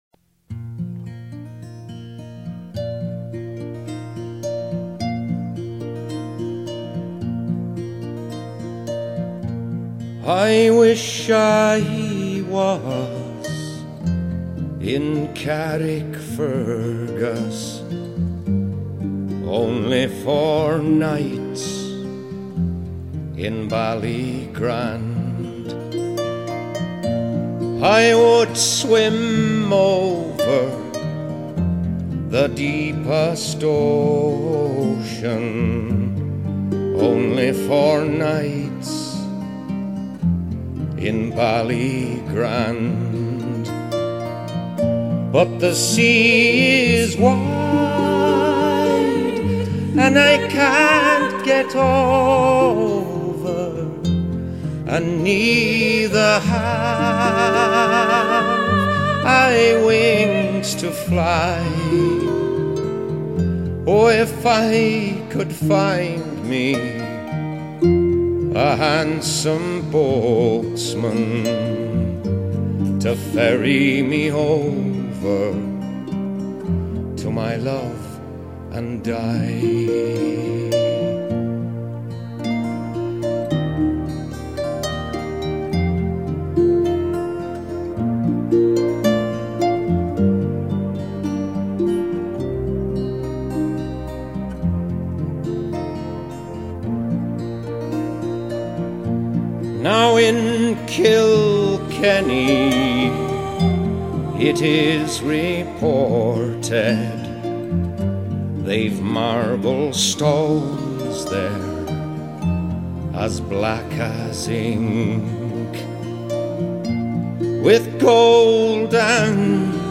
音乐类别：爵士人声
无论全碟音乐的编排，环境氛围的烘托，小鸟等自然声音的渲染，更有和男子的合唱，
一切的一切，目的就是为了表现凯尔特音乐特有的旋律和味道。